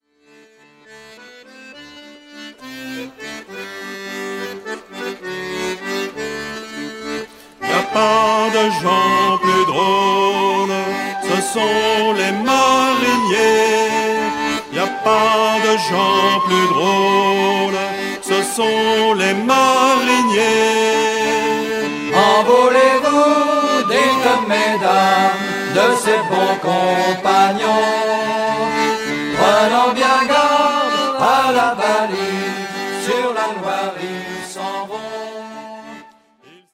chants de marine de Loire